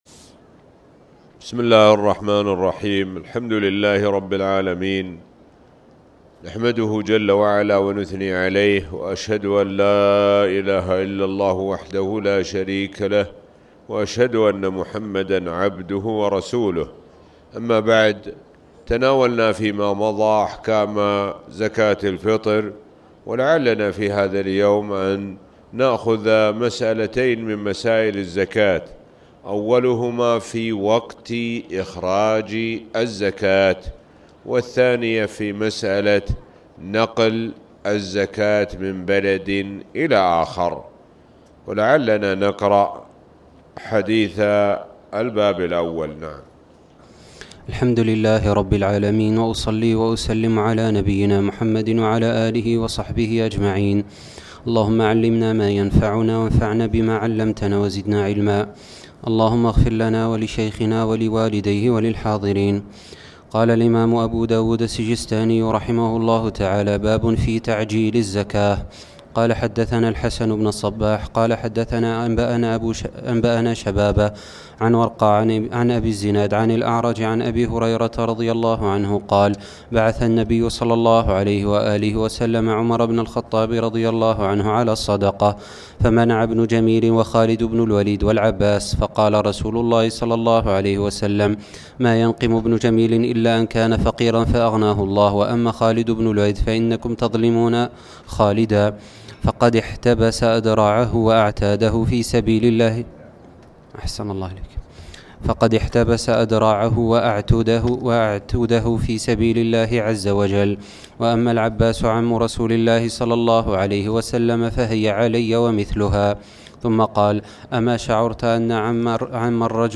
تاريخ النشر ١١ رمضان ١٤٣٨ هـ المكان: المسجد الحرام الشيخ: معالي الشيخ د. سعد بن ناصر الشثري معالي الشيخ د. سعد بن ناصر الشثري زكاة الفطر